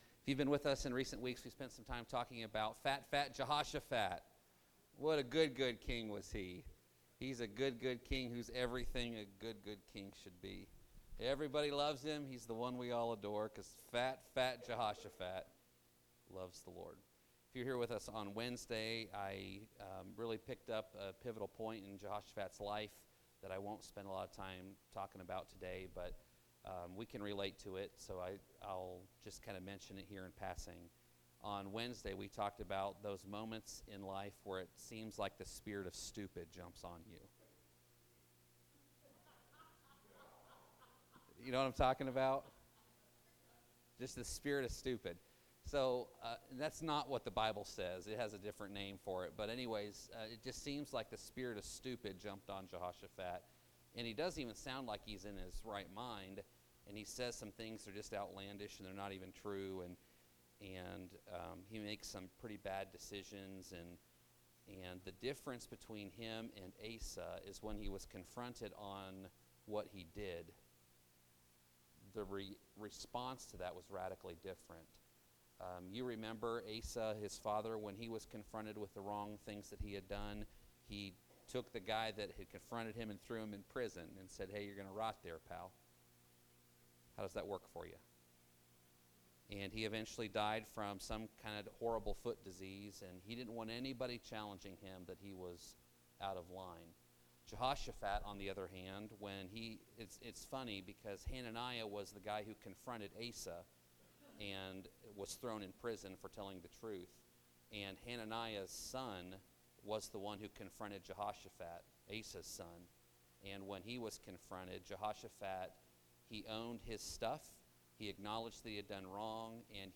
SRC Church of the Nazarene Sermons